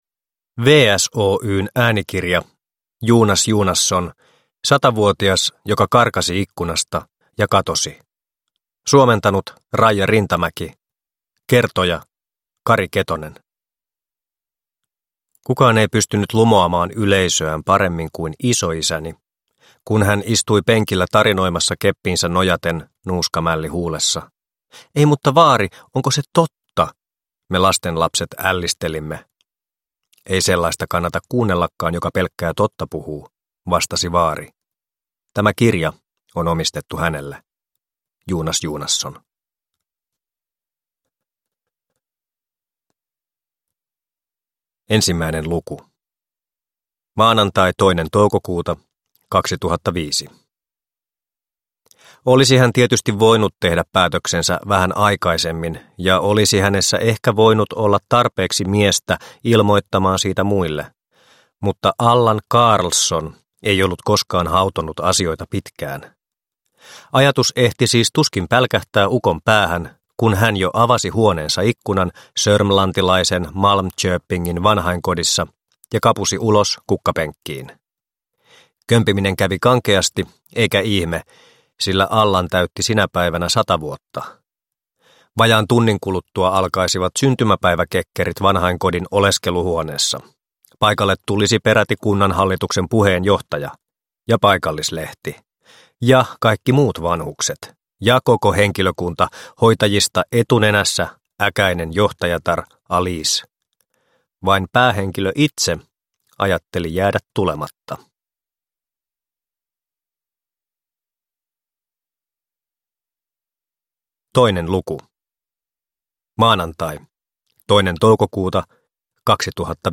Satavuotias joka karkasi ikkunasta ja katosi – Ljudbok